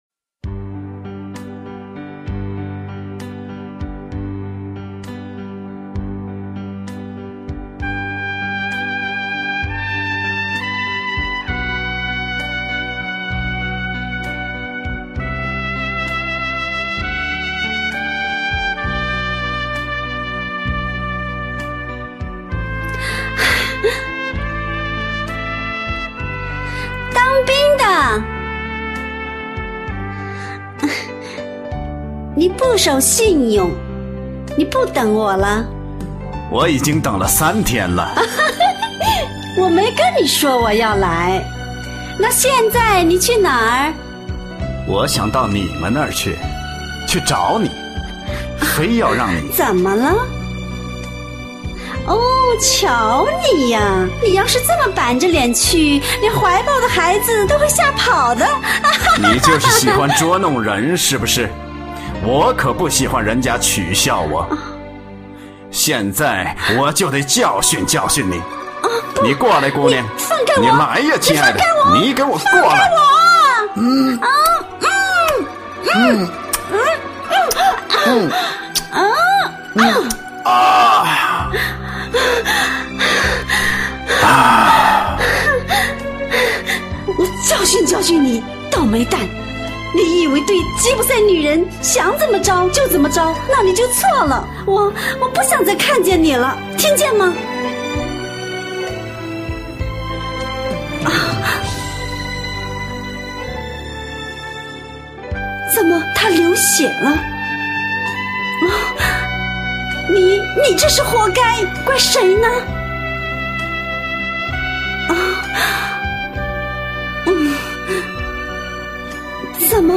《葉塞尼亞 》配音片段